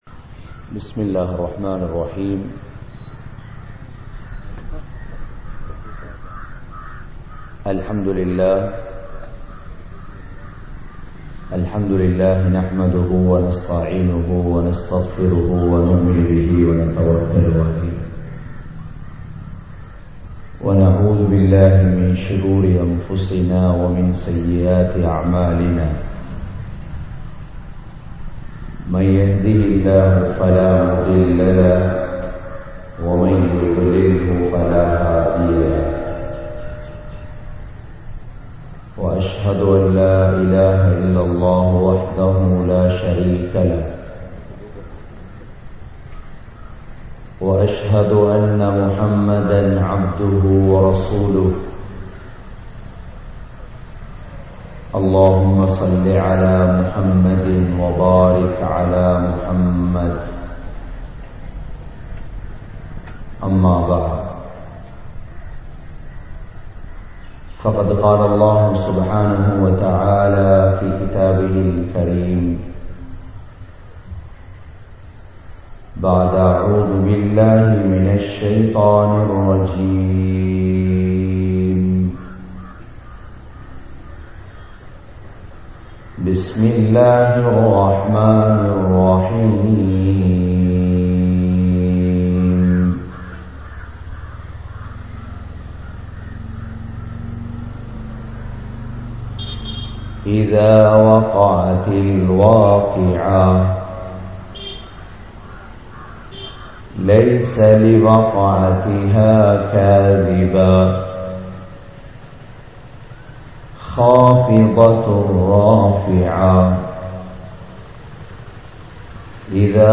Naan Yaar? | Audio Bayans | All Ceylon Muslim Youth Community | Addalaichenai